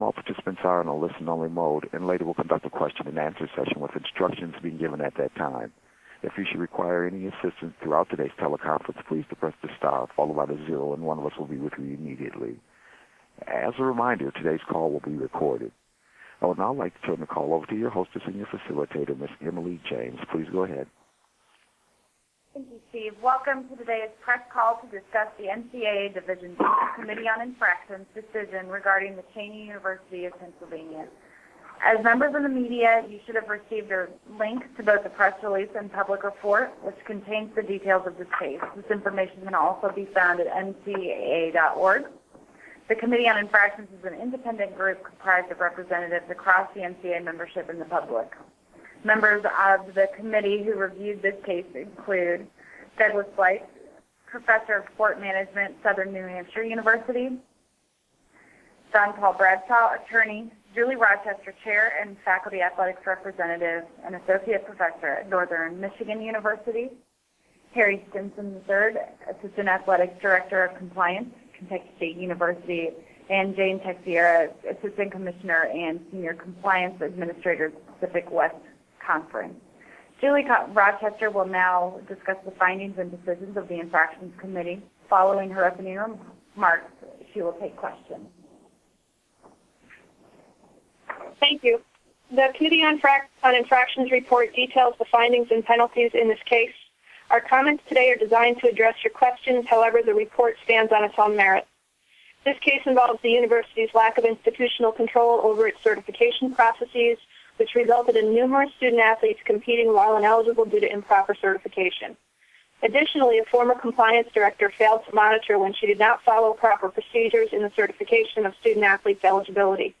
Media Teleconference Recording - NCAA Division II Committee on Infractrions - Cheyney University of Pennsylvania